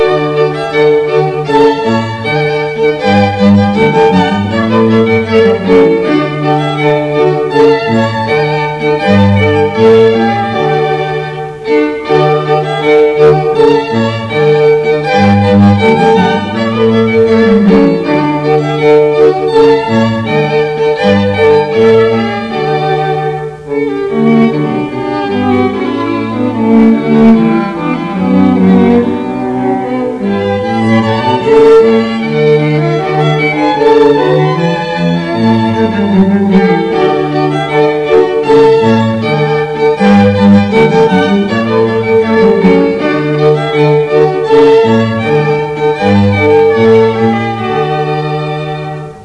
Serenade String Quartet Music Examples
Classical Music Samples